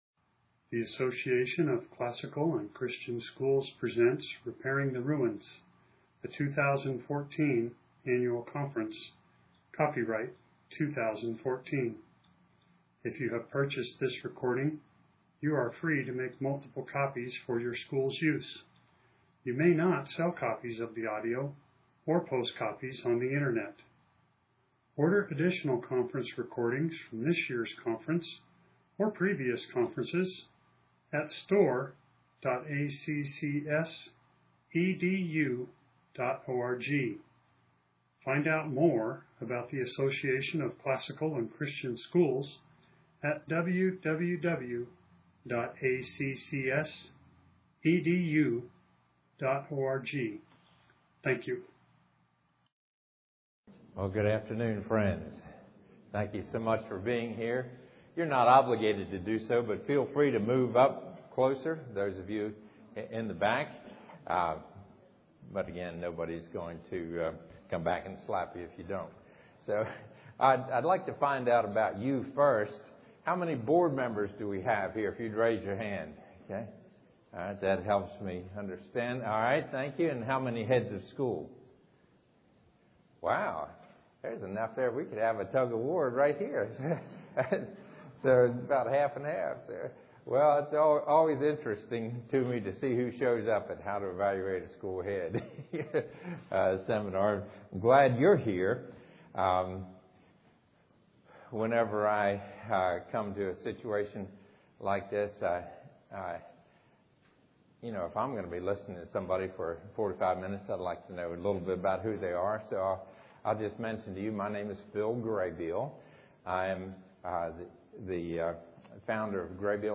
2014 Leaders Day Talk | 0:47:37 | Leadership & Strategic